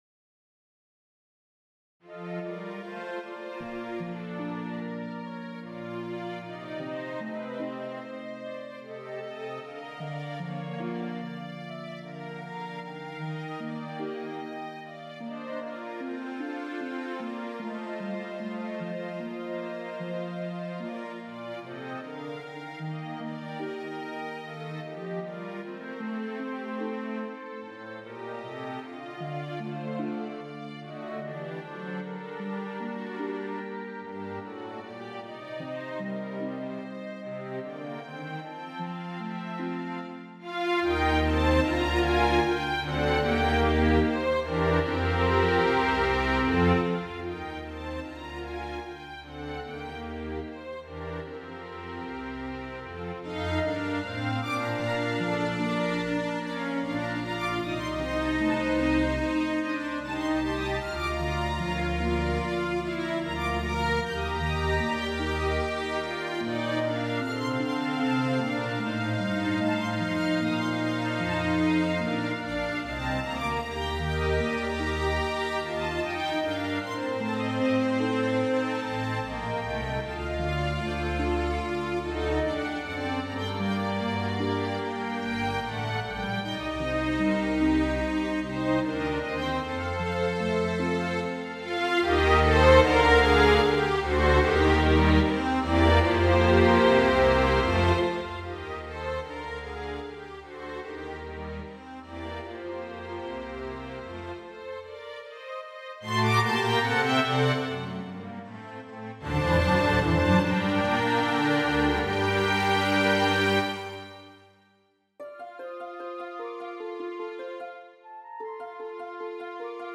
Flowers by the Lake (for Flute, Harp & Strings)
Here my new piece for Flute, Harp and Strings.